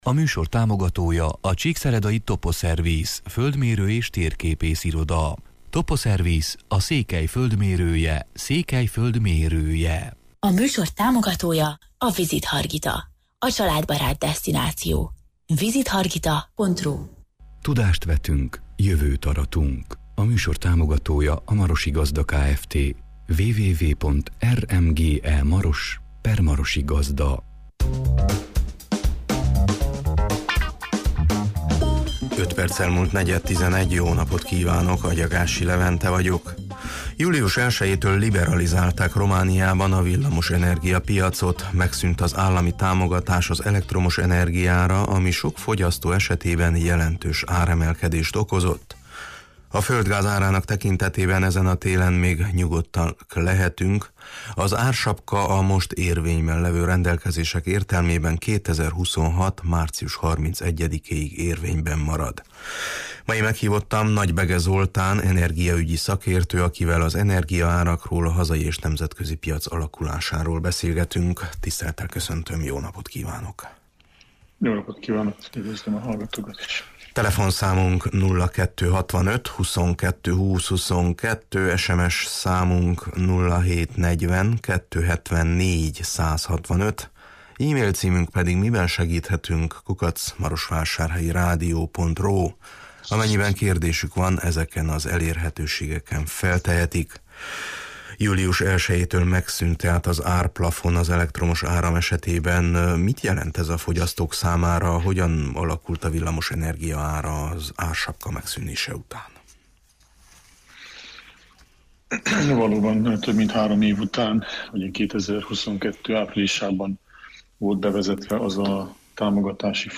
energiaügyi szakértő, akivel az energiaárakról, a hazai és nemzetközi piac alakulásáról beszélgetünk: